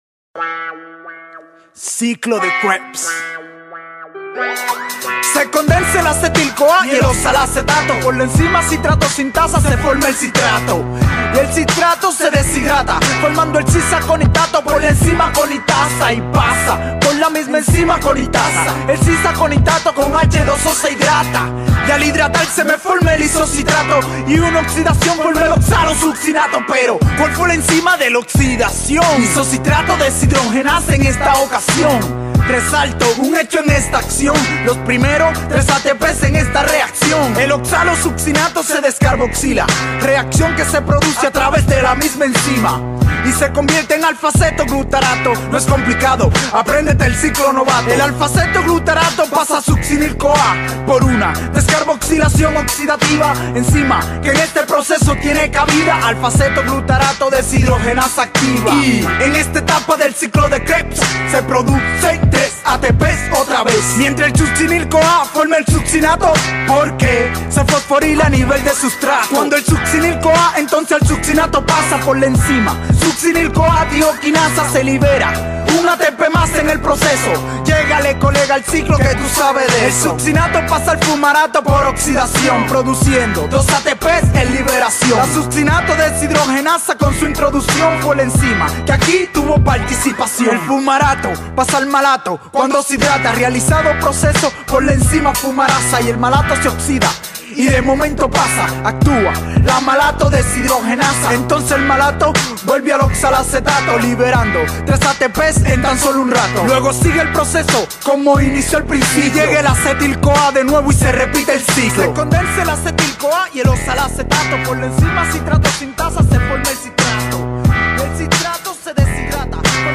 KrebsRap_ES.mp3